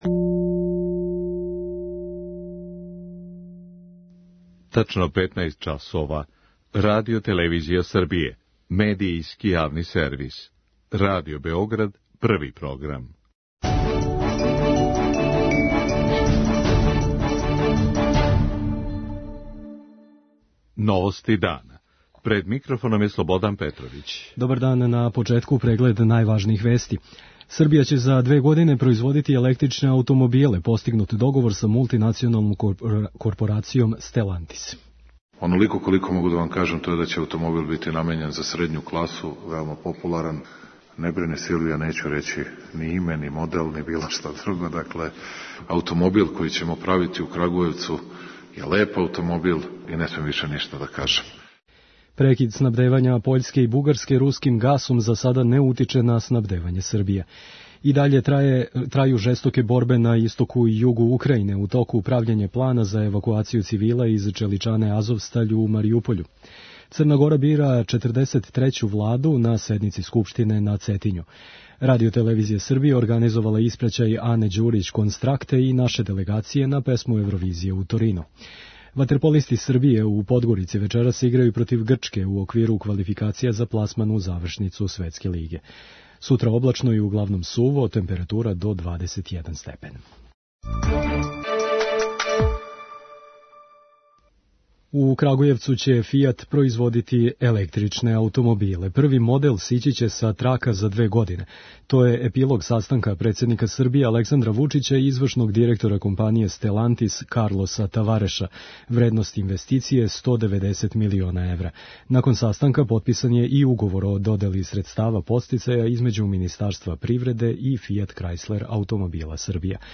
Укупна вредност инвестиције је 190 милиона евра, од чега Србија даје 48 милиона. преузми : 6.34 MB Новости дана Autor: Радио Београд 1 “Новости дана”, централна информативна емисија Првог програма Радио Београда емитује се од јесени 1958. године.